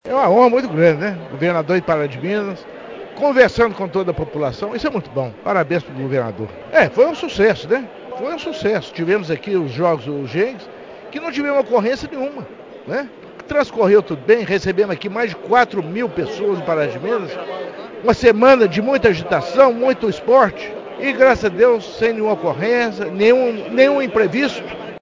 O evento ocorreu na quadra poliesportiva da Escola Estadual Nossa Senhora Auxiliadora, no bairro São Cristóvão, com a presença do governador do Estado de Minas Gerais, Romeu Zema, que ressaltou a importância de Pará de Minas para o desporto estudantil.